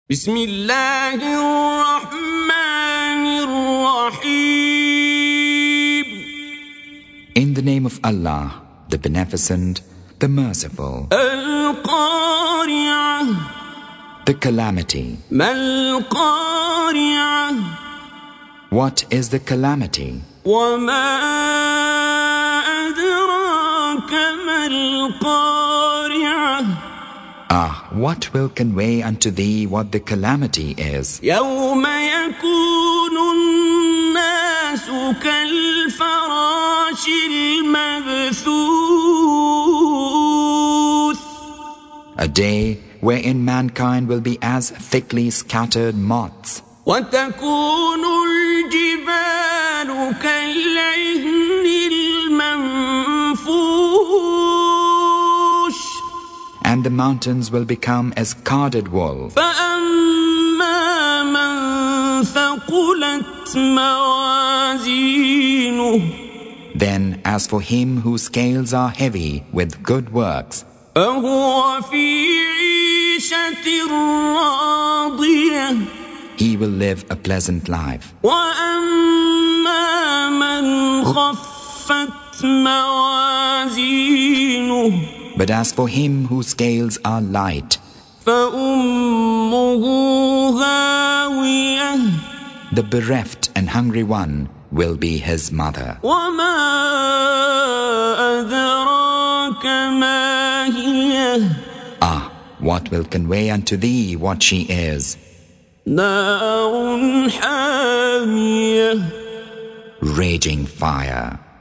Surah Sequence تتابع السورة Download Surah حمّل السورة Reciting Mutarjamah Translation Audio for 101. Surah Al-Q�ri'ah سورة القارعة N.B *Surah Includes Al-Basmalah Reciters Sequents تتابع التلاوات Reciters Repeats تكرار التلاوات